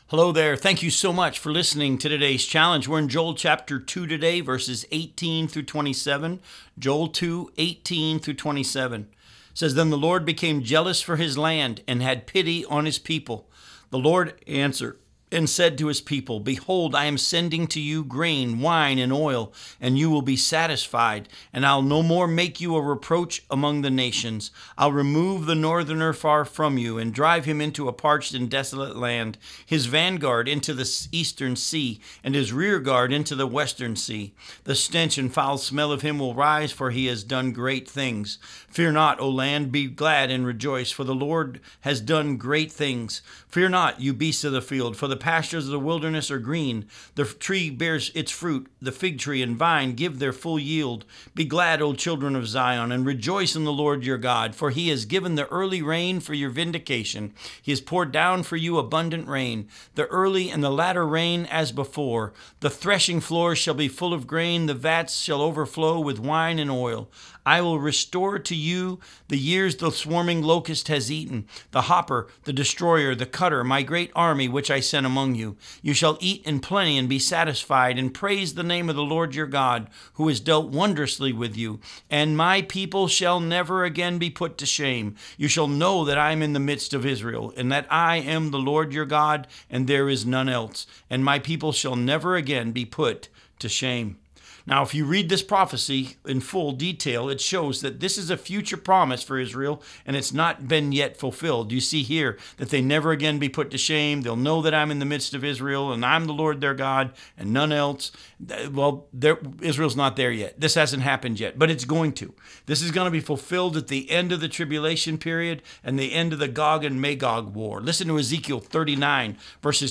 Challenge for Today Radio Program